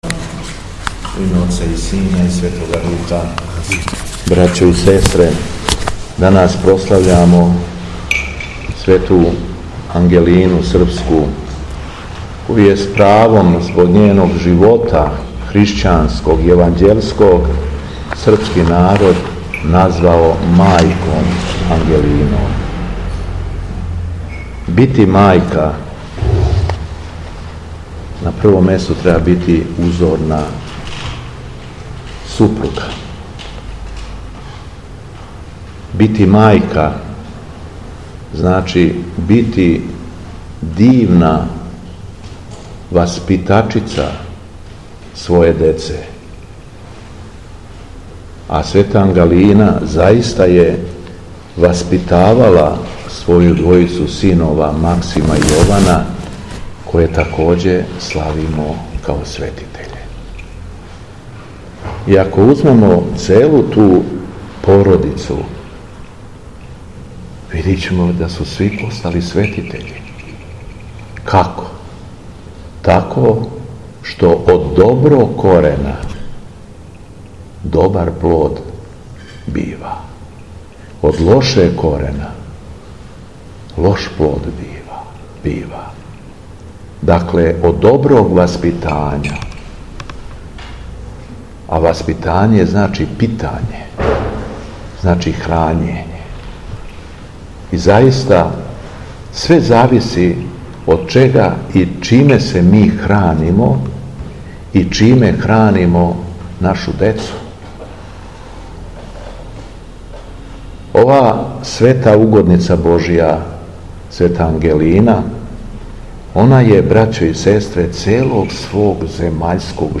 Беседа Његовог Високопреосвештенства Митрополита шумадијског г. Јована
У наставку литургије, после Јеванђеља, Високопреосвећени обратио се верном народу надахнутом беседом: